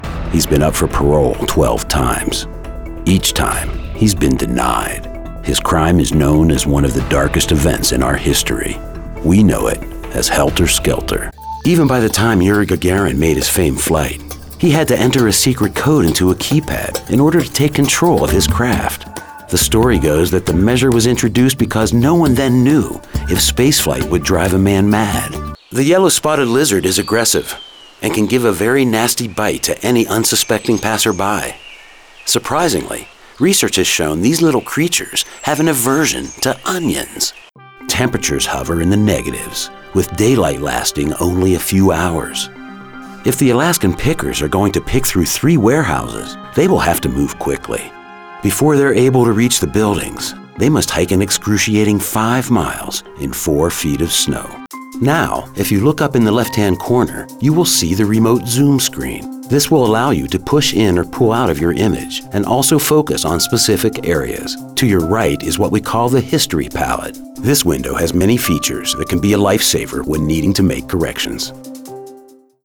Male
Authoritative, Character, Confident, Cool, Corporate, Deep, Engaging, Friendly, Gravitas, Posh, Reassuring, Smooth, Streetwise, Warm, Versatile, Conversational, Funny, Sarcastic, Assured, Upbeat
Generic unaccented American English (native), Western Pennsylvania (native), New York, Texas, Generic Southern US, West Virginia/Appalachian, Generic Eastern European
A voice like smooth gravel—deep, textured, and riveting.
Narration.mp3
Audio equipment: Pro, acoustically treated studio with Source-Connect, Grace m101 preamp, Audient iD4 interface